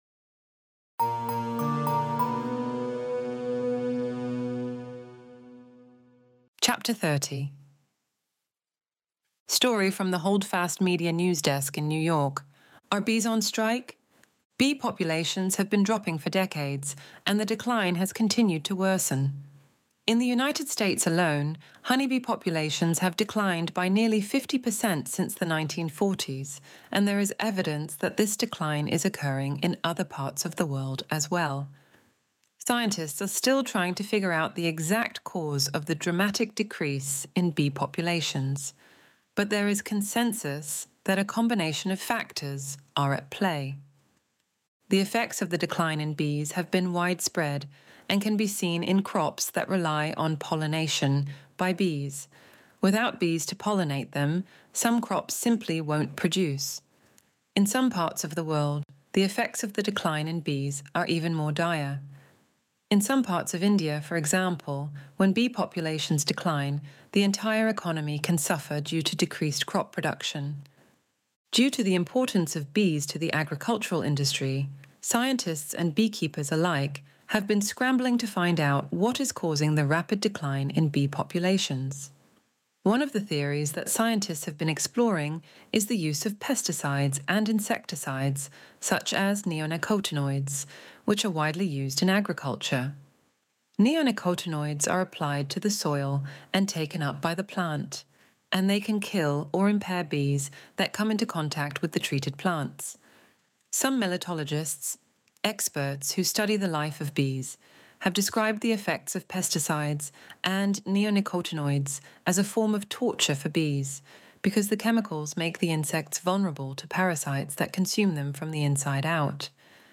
Extinction Event Audiobook Chapter 30